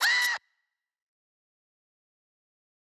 Tm8_Chant1.wav